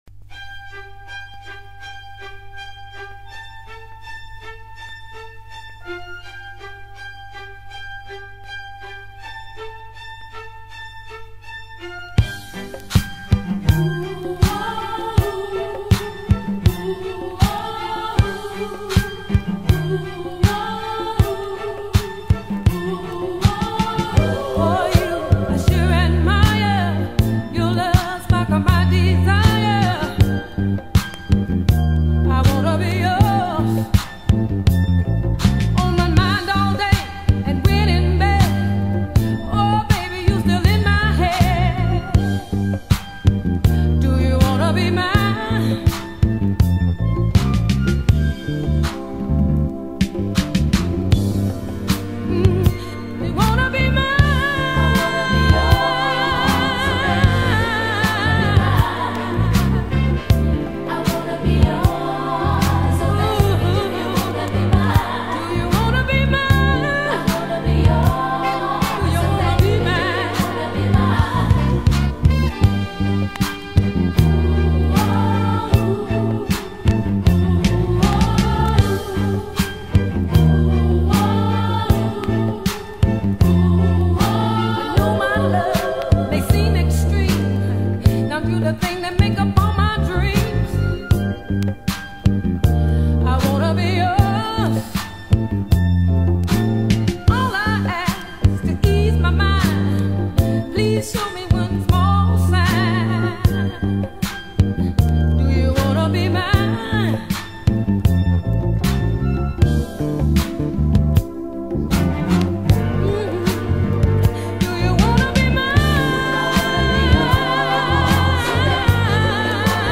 Jazz-funk, années 70